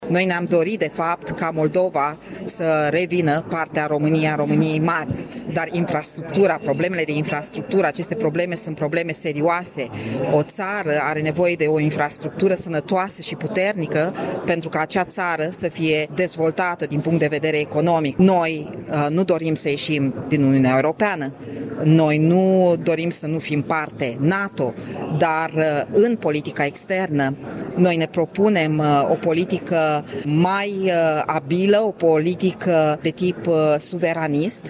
Deputatul AUR de Iași, Cristina Dascălu a declarat că formațiunea pe care o reprezintă va intra în opoziție, dar militează pentru proiectele unioniste rferitoare la Republica Moldova.